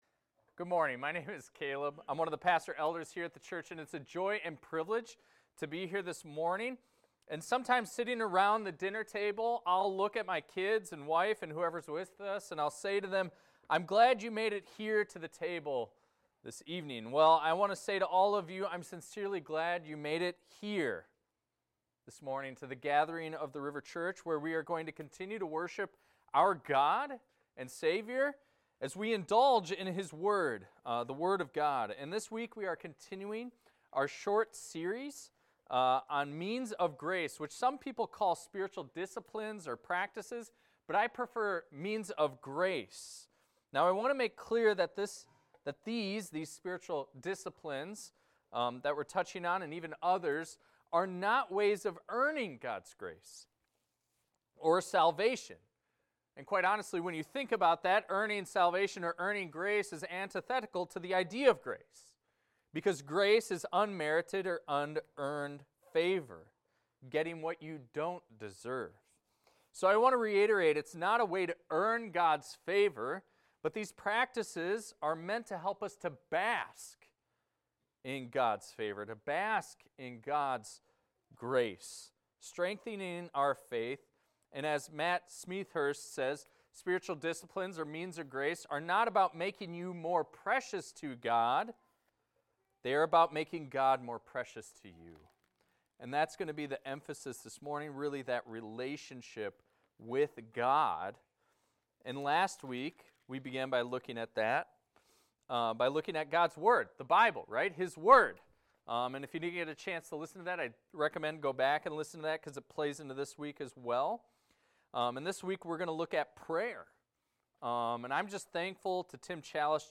This is a recording of a sermon titled, "The Power of Prayer."